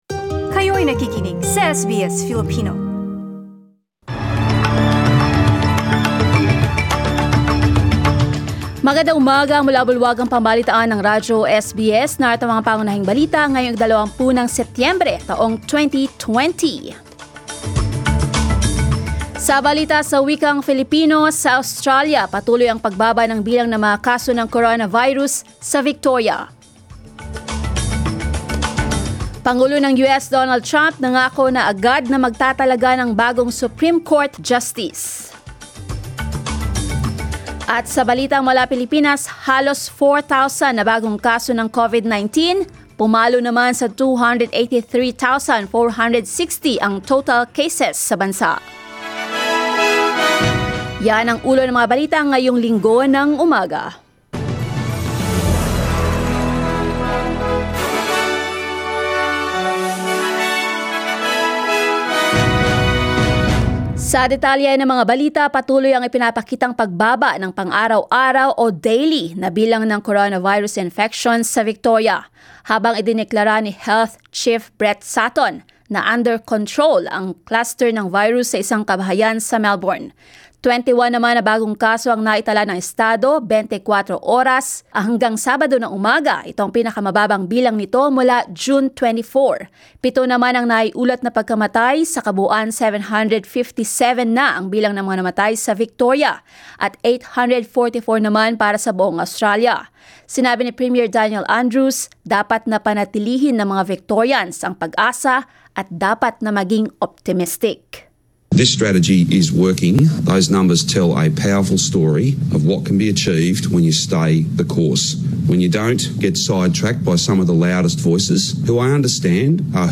SBS News in Filipino, Sunday 20 September